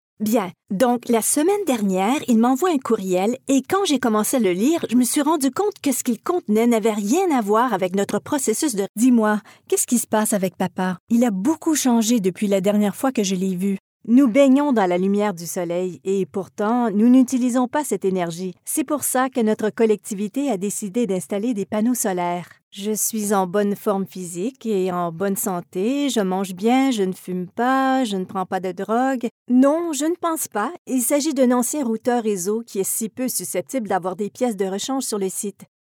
A clear, natural and trustworthy French Canadian female voice actress that has completed many high profiles projects in North America and abroad. Her voice range is between 20 and 40.
kanadisch-fr
Sprechprobe: Sonstiges (Muttersprache):